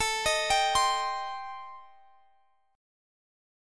Am7b5 Chord
Listen to Am7b5 strummed